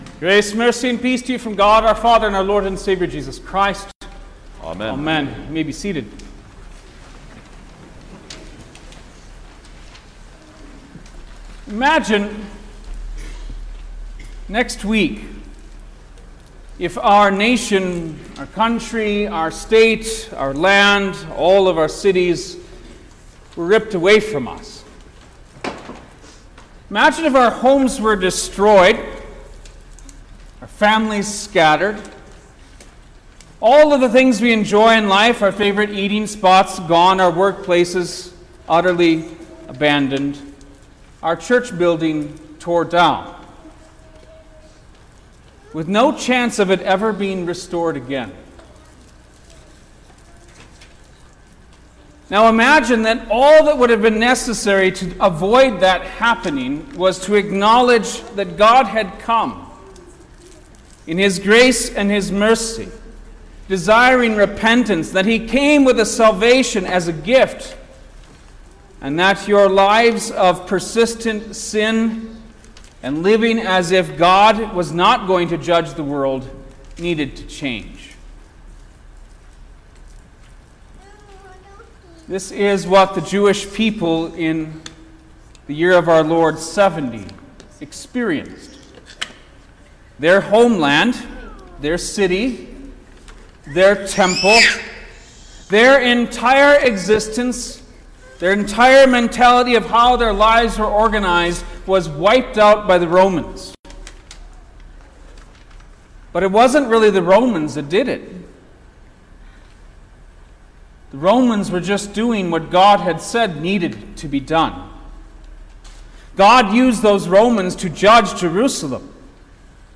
August-4_2024_Tenth-Sunday-after-Trinity_Sermon-Stereo.mp3